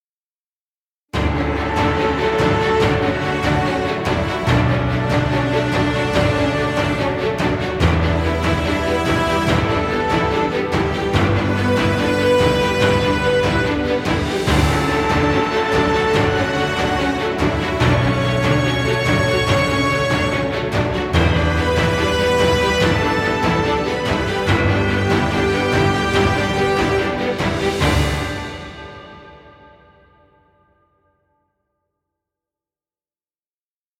Inspirational epic music.